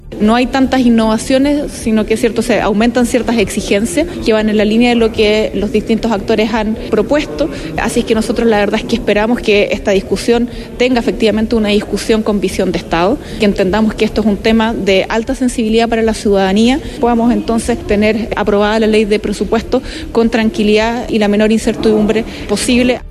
La subsecretaria Valentina Quiroga, indicó que se han respetado las visiones políticas que se propusieron el año pasado para mejorar el mecanismo.